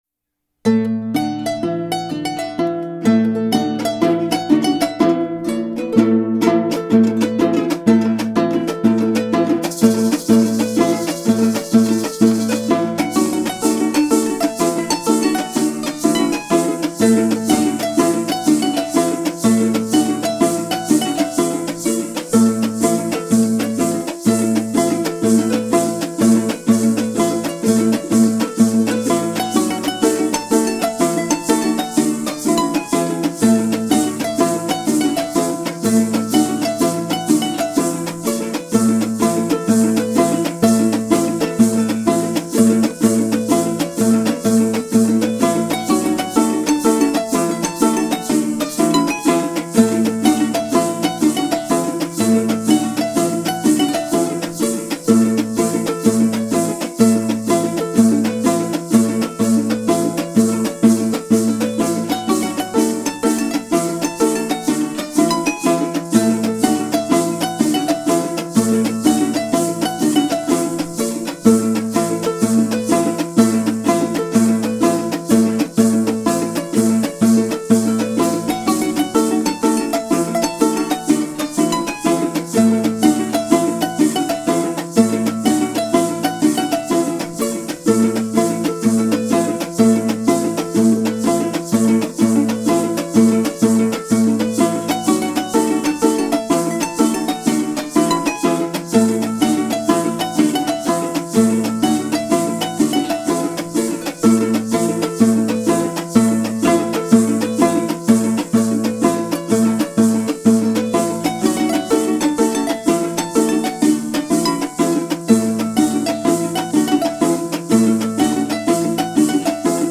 Música de arpa                                                                                 en los rituales del Costumbre:                                                                                            teenek, nahuas y totonacos
Grabaciones de Campo
jarana huasteca